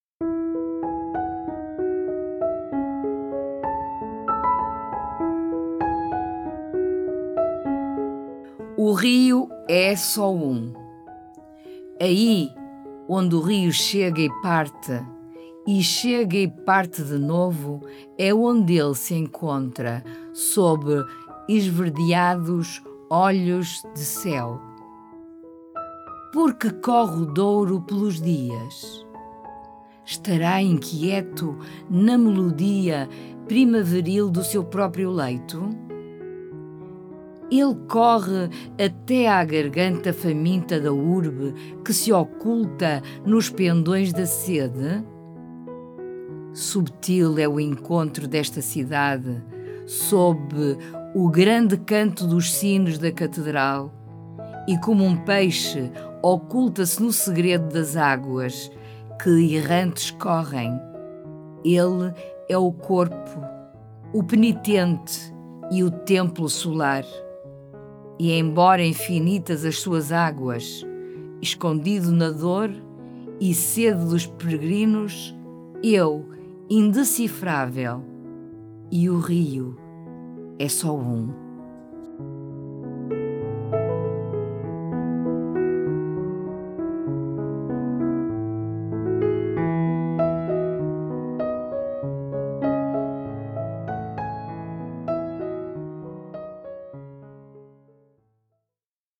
Música: Invisible beauty, de Aakash Gandhi.